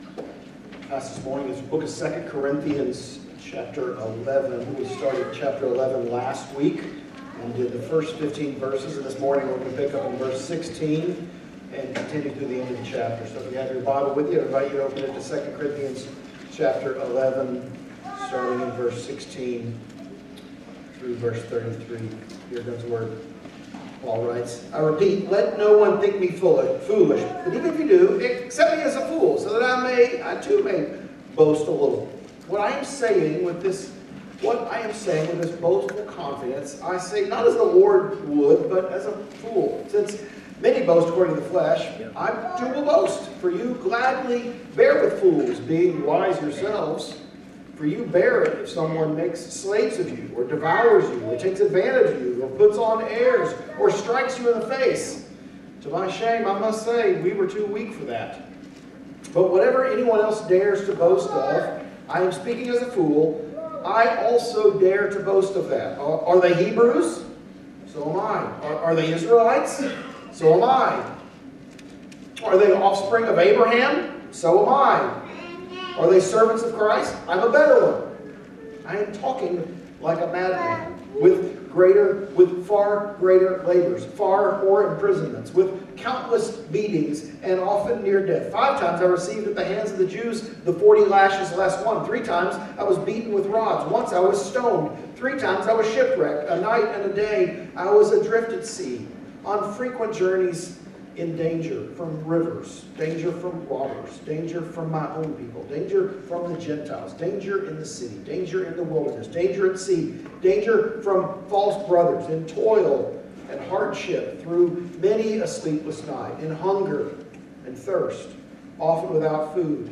Sermons | Hope Church PCA